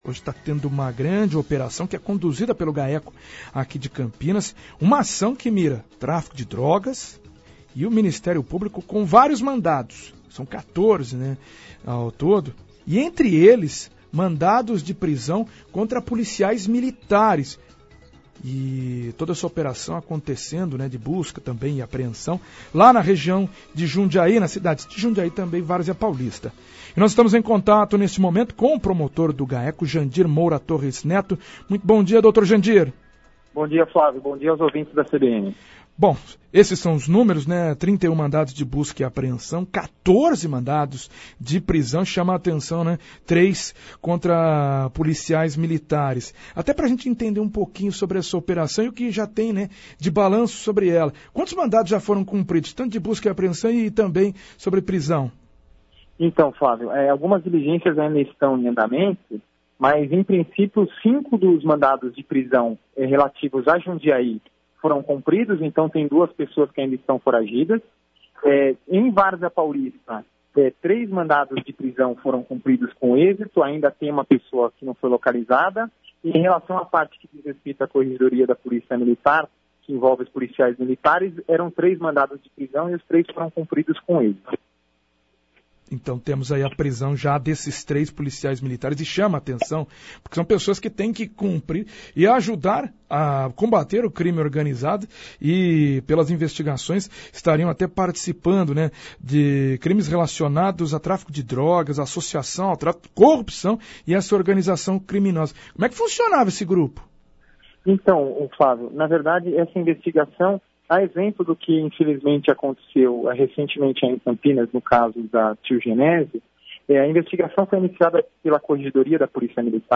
Promotor do Gaeco, Jandir Moura Torres Neto fala sobre operação que acontece na região aonde já foram presos 3 PMs